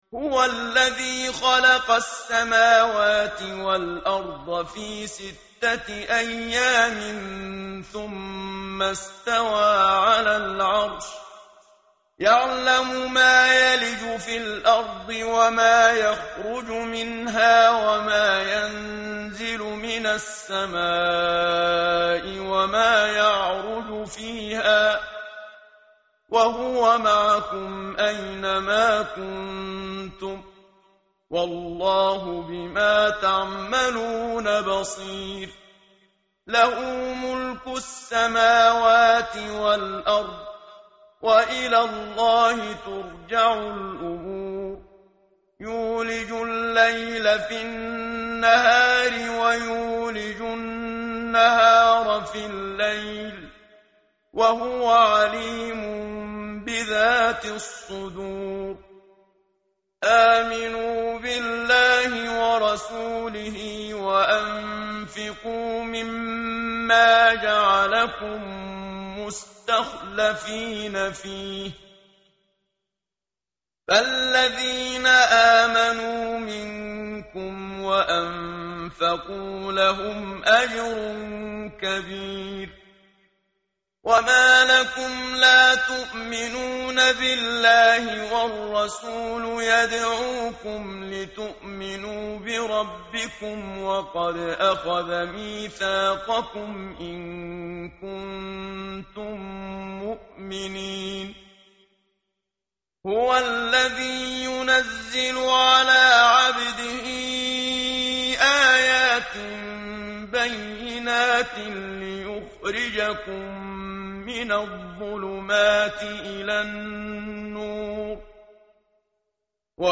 tartil_menshavi_page_538.mp3